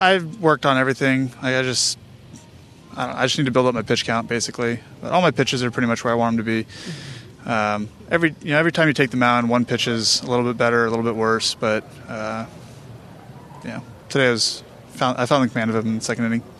The Los Angeles native addressed reporters from the Dodgers spring training site in Glendale, Arizona, Tuesday.